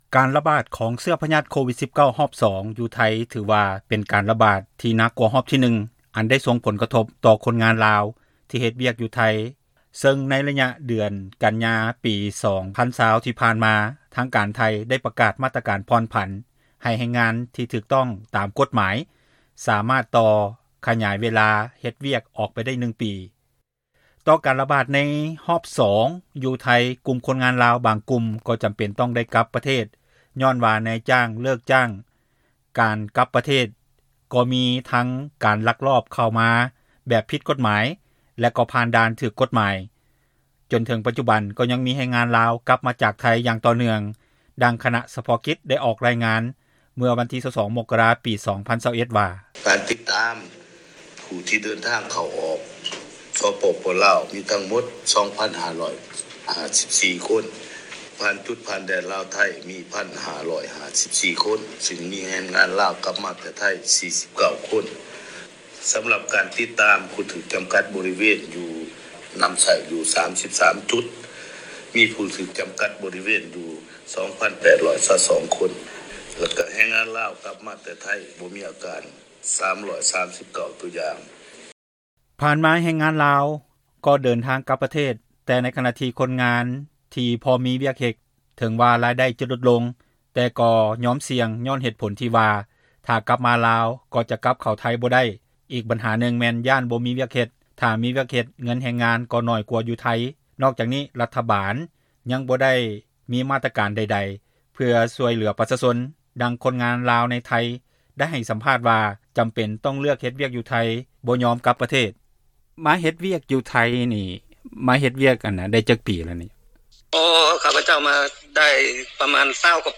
ສຳພາດຄົນງານລາວຢູ່ໄທຍ.